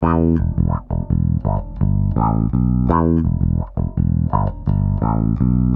wahfunk.mp3